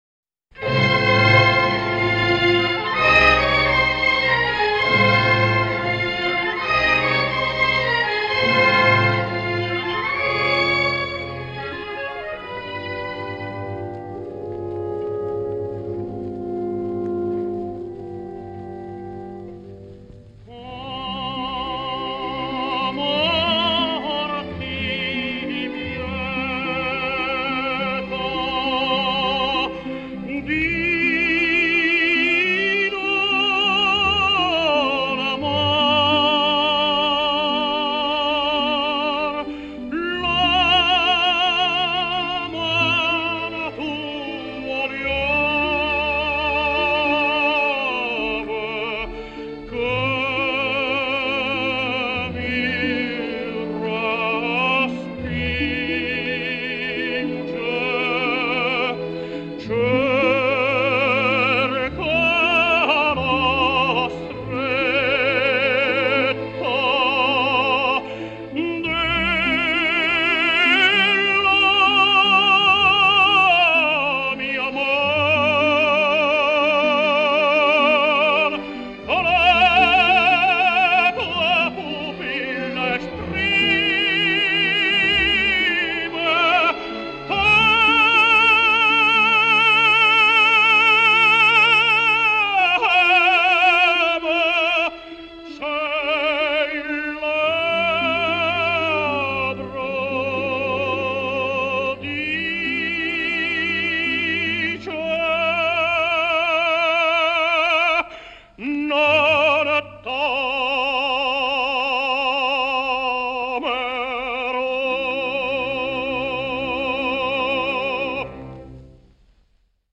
Italian Tenor